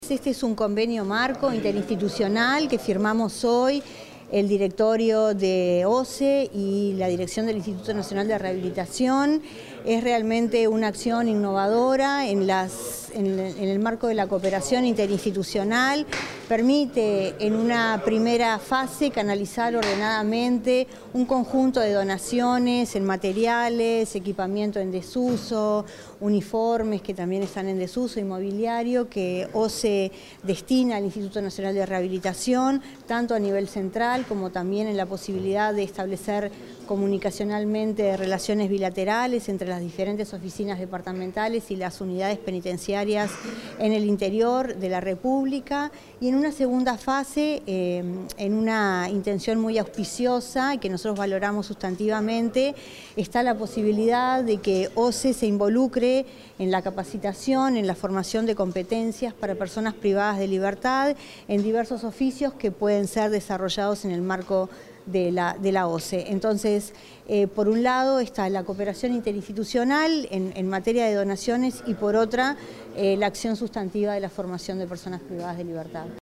Declaraciones de la directora del INR, Ana Juanche
En la firma del convenio para la donación de insumos entre OSE y el Instituto Nacional de Rehabilitación, la directora del INR, Ana Juanche, diálogo